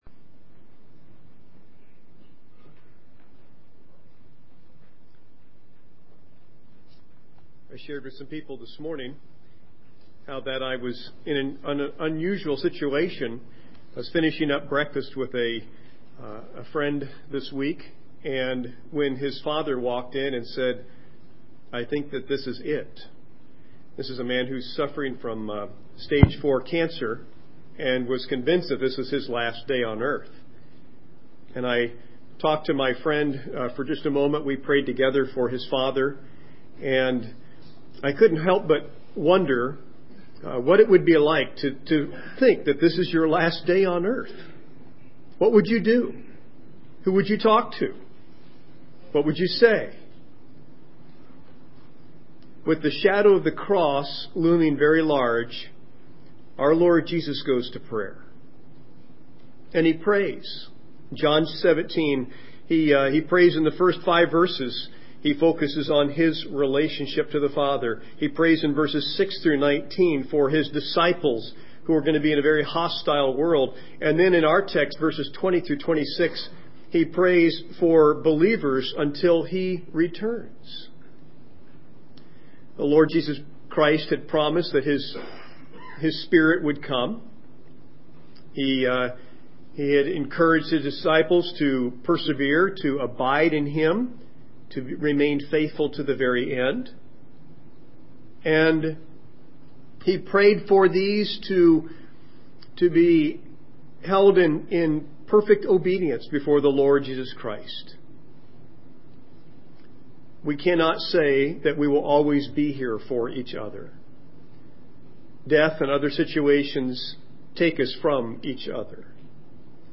Service Sunday Morning